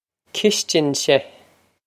Cistinse Kish-tin-sheh
This is an approximate phonetic pronunciation of the phrase.